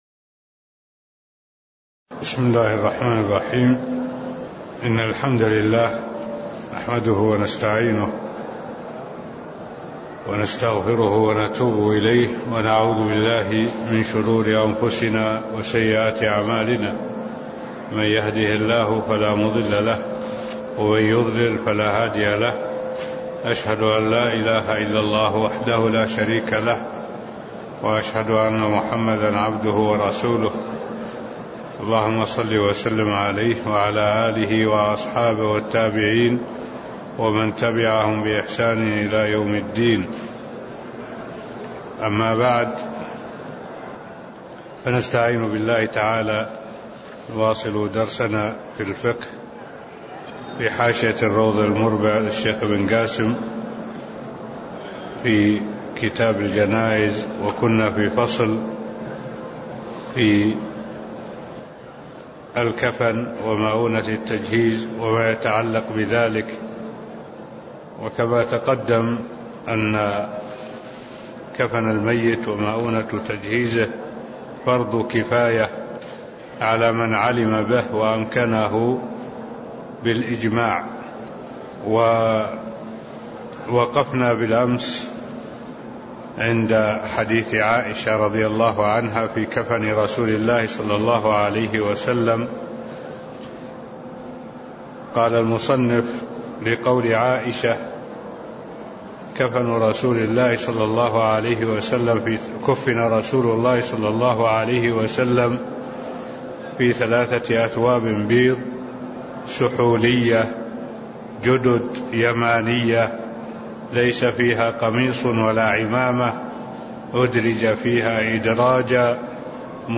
تاريخ النشر ٢٦ محرم ١٤٢٩ هـ المكان: المسجد النبوي الشيخ: معالي الشيخ الدكتور صالح بن عبد الله العبود معالي الشيخ الدكتور صالح بن عبد الله العبود الكفن ومؤنة تجهيزه (001)